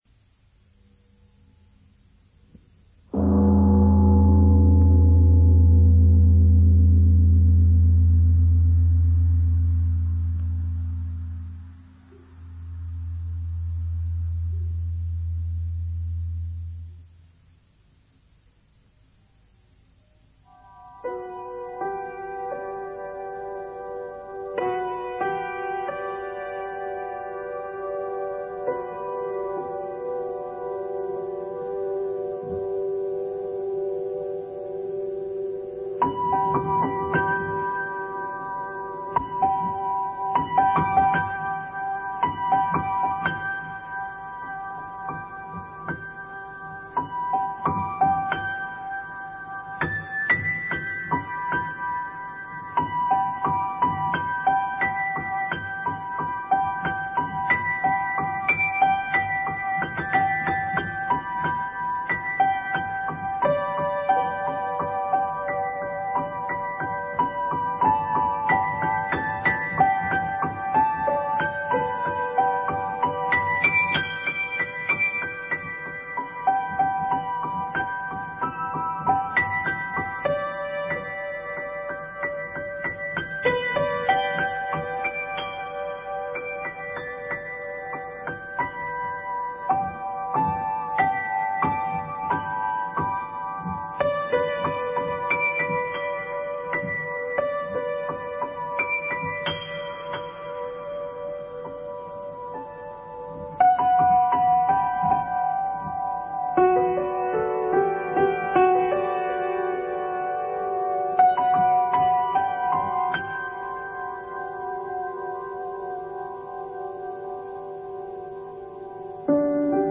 Sit quietly, while the music is playing.